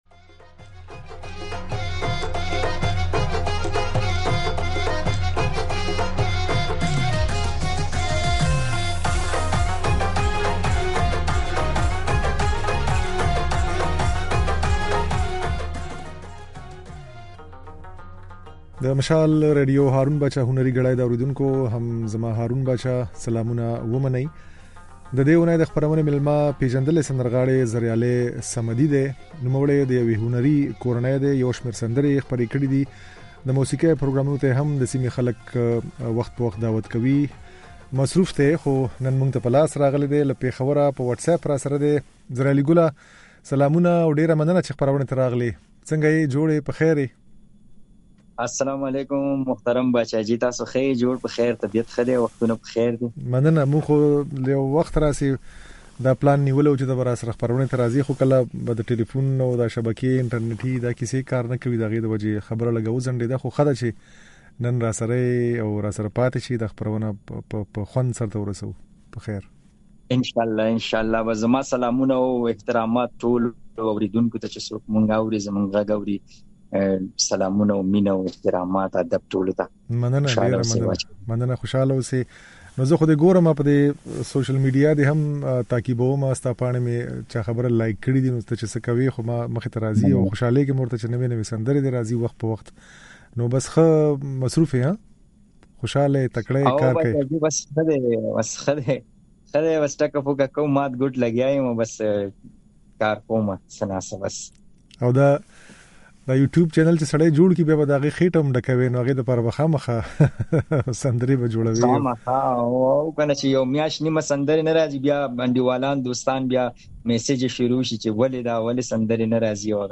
ځينې نوې سندرې يې اورېدای شئ